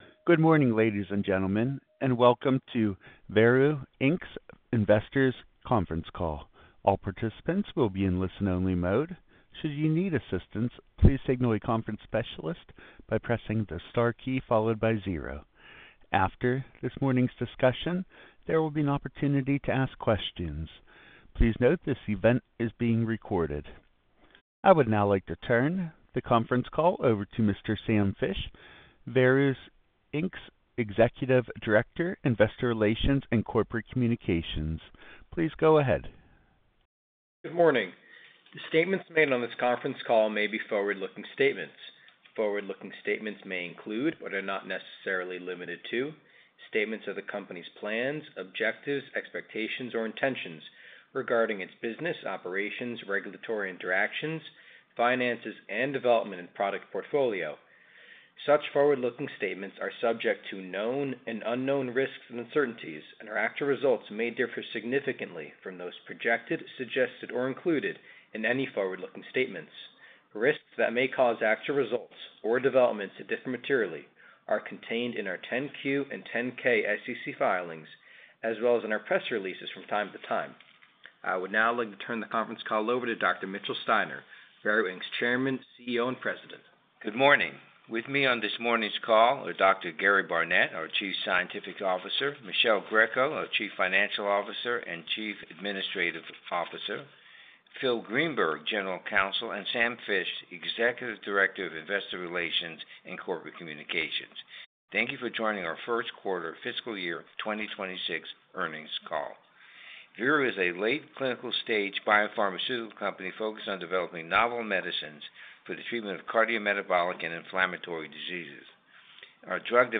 FY 2026 Q1 Earnings Conference Call